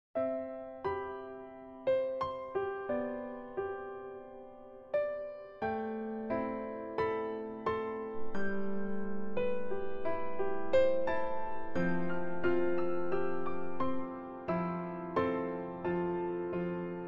WIFI Motion Sensor With Alarm sound effects free download
WIFI Motion Sensor With Alarm Sound WL-812WT